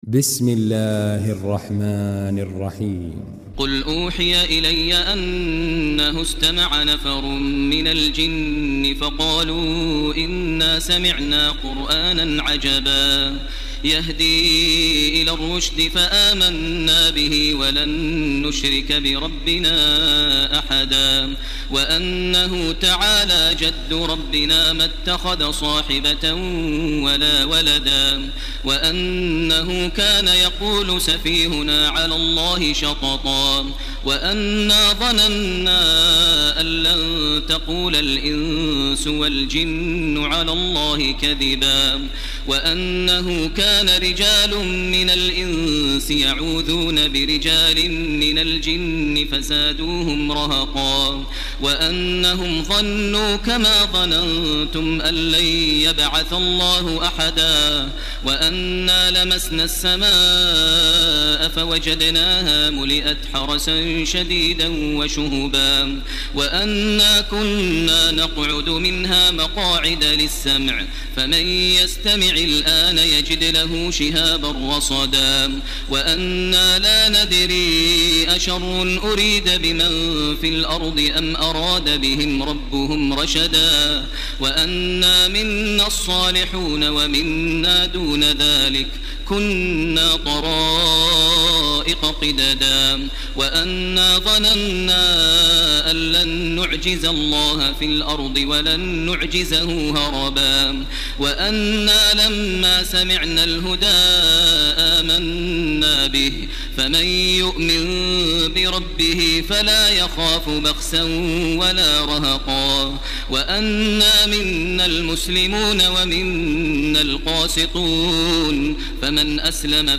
تراويح ليلة 28 رمضان 1430هـ من سورة الجن الى المرسلات Taraweeh 28 st night Ramadan 1430H from Surah Al-Jinn to Al-Mursalaat > تراويح الحرم المكي عام 1430 🕋 > التراويح - تلاوات الحرمين